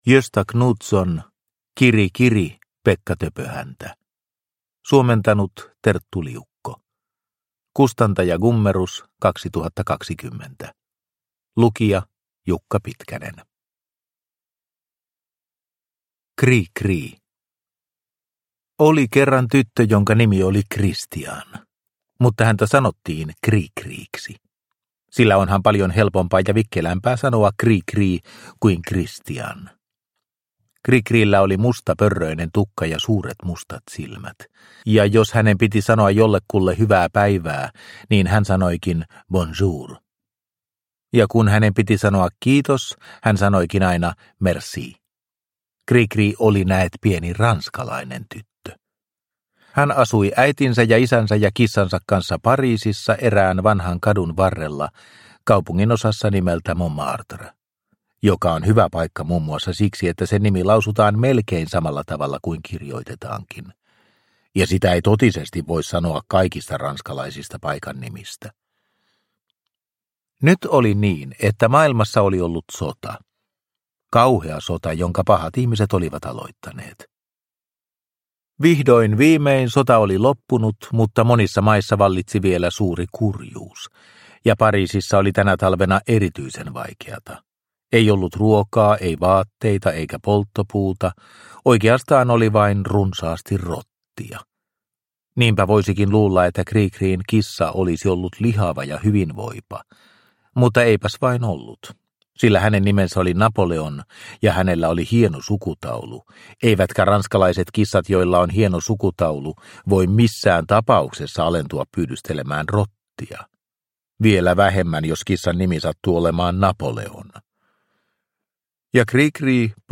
Kiri kiri, Pekka Töpöhäntä – Ljudbok – Laddas ner